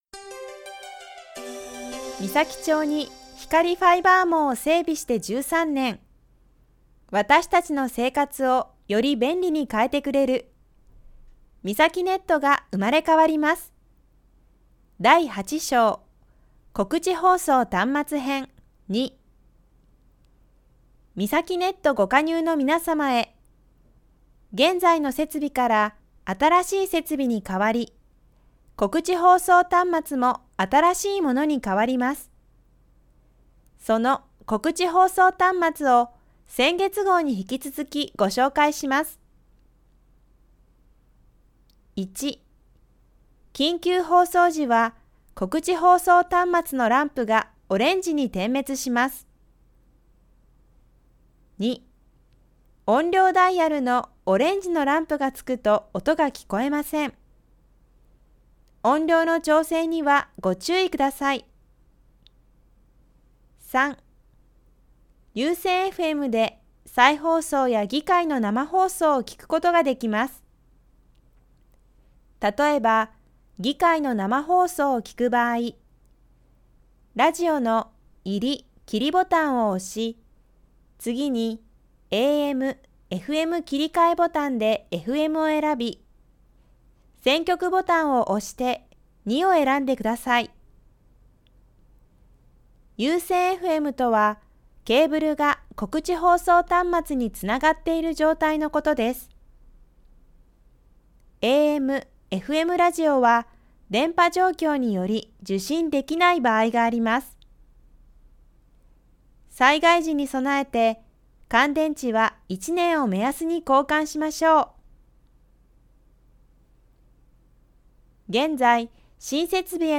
２０１９年声の広報みさき６月号 声の広報 広報誌の一部を読み上げています。